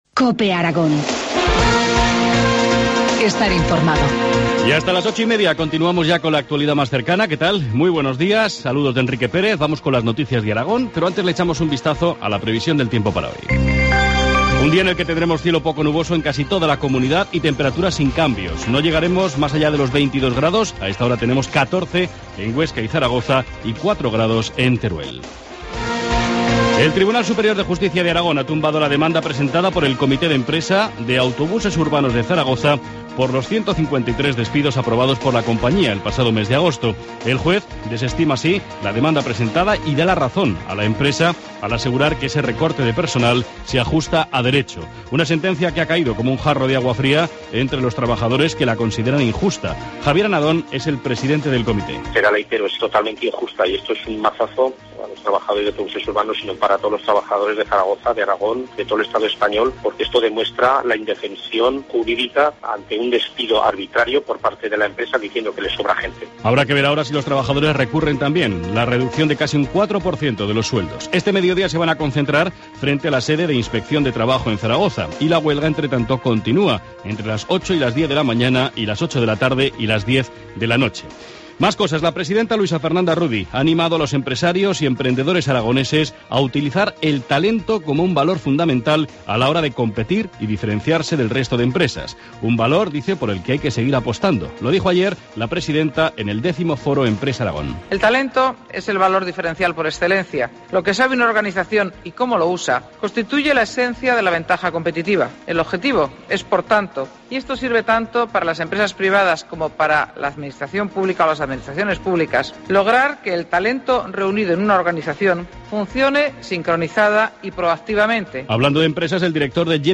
Informativo matinal, jueevs 7 de noviembre, 8.25 horas